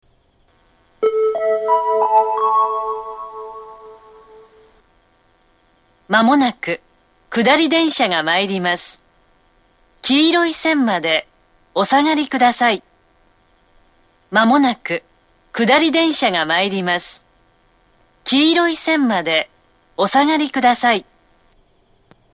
２番線接近放送